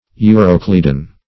Euroclydon \Eu*roc"ly*don\, n. [NL., fr. Gr.